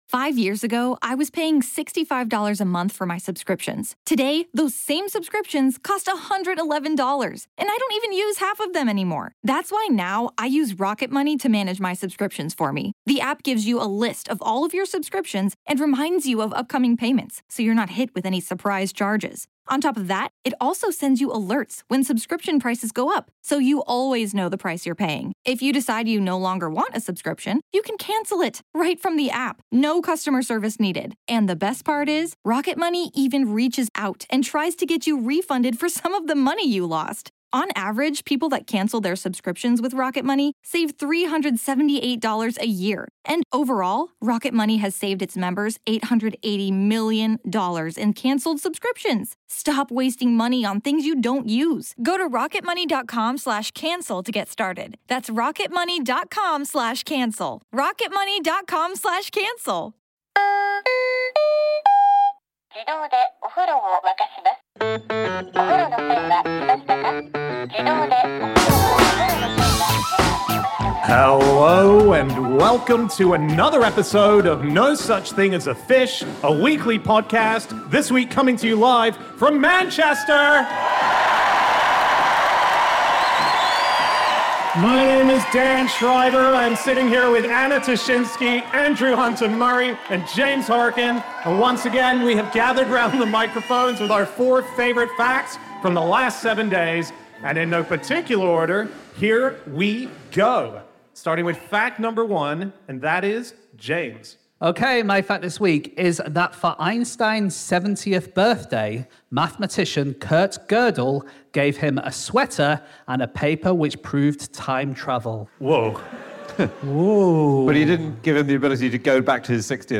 Live from Manchester